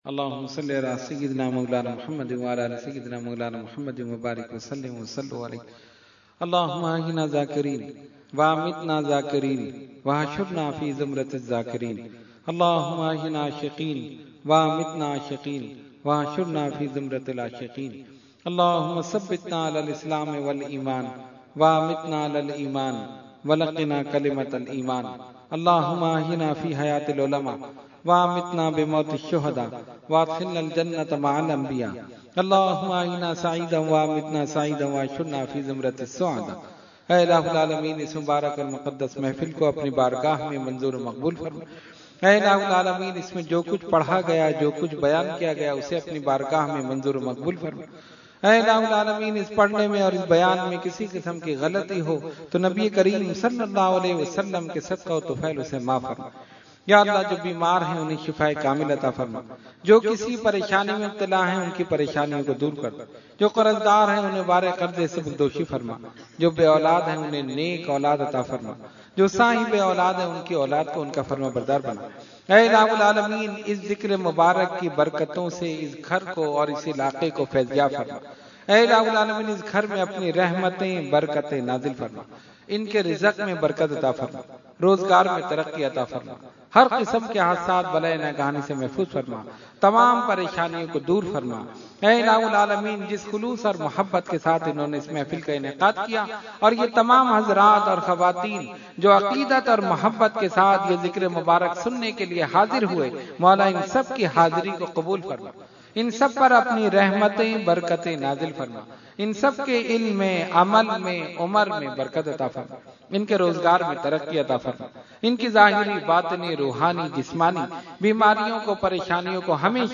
Dua Mehfil At PECHS Society Karachi 2015 – Dargah Alia Ashrafia Karachi Pakistan
Category : Dua | Language : UrduEvent : Mehfil PECHS Society Khi 2015